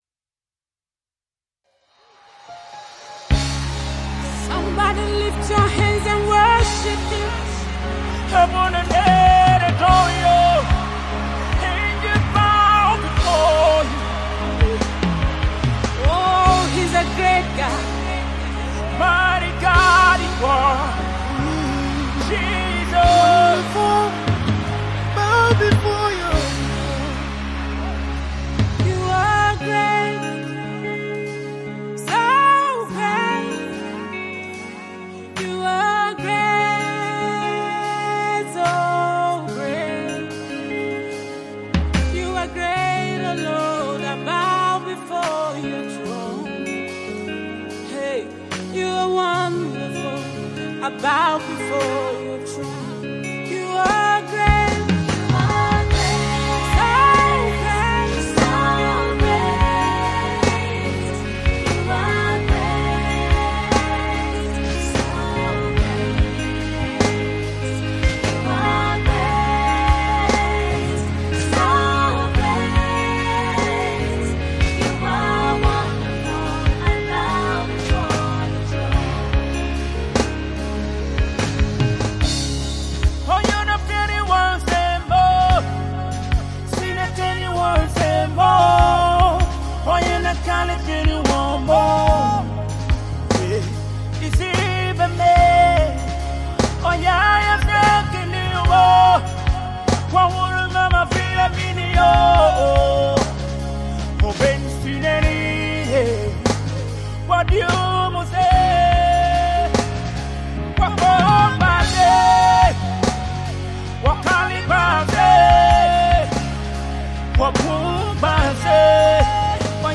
Up and coming gospel music sensation